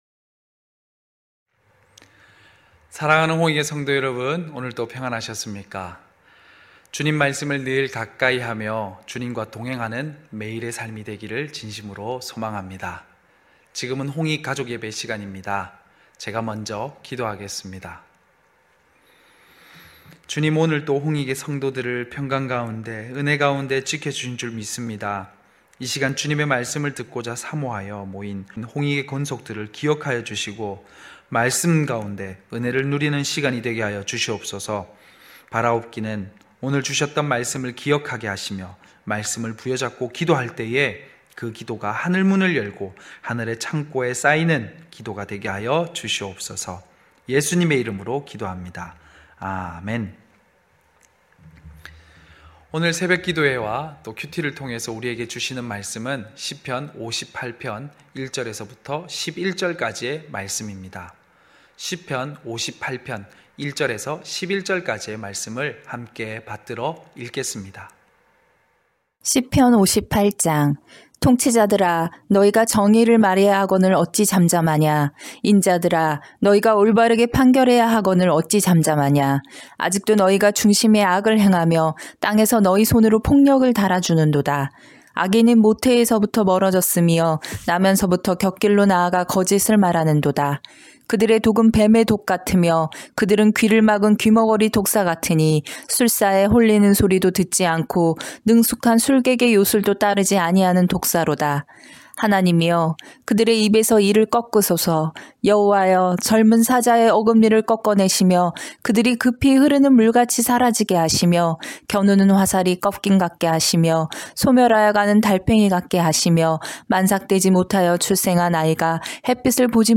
9시홍익가족예배(3월8일).mp3